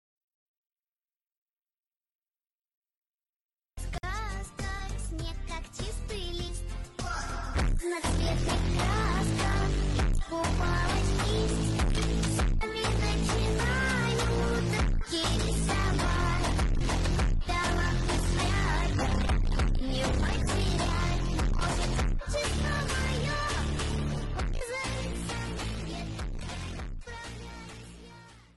rosyjska piosenka folk z połączeniem nowoczesności